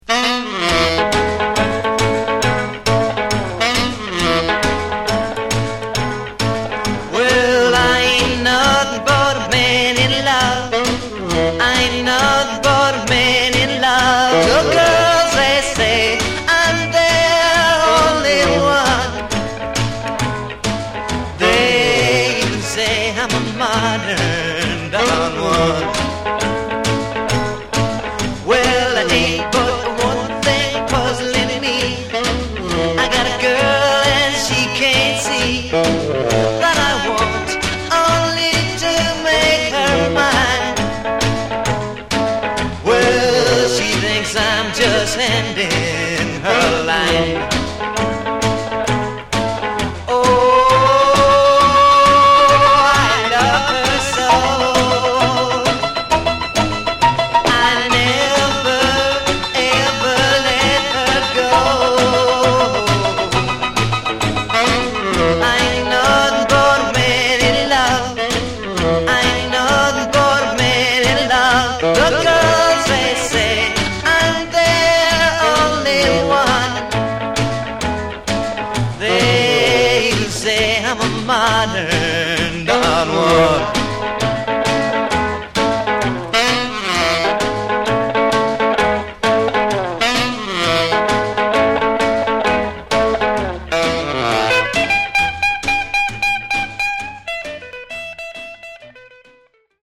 Genre: Rockabilly/Retro
early rockabilly number